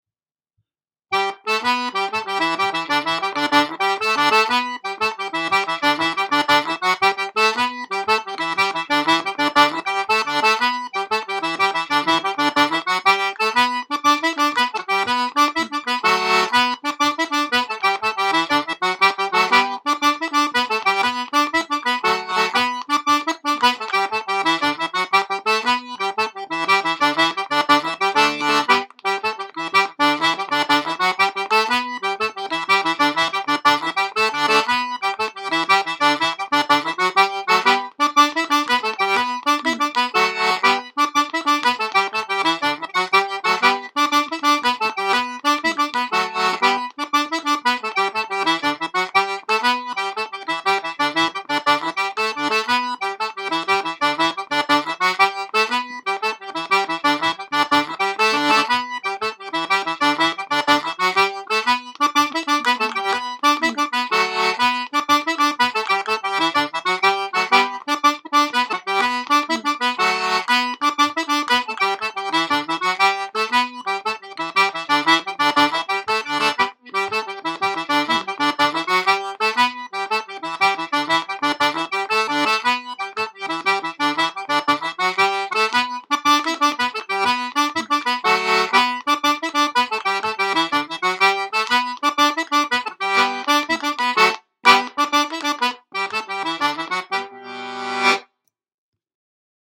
Na Ceannabháin Bhána (120 bpm